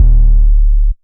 Jet Fuel 808.wav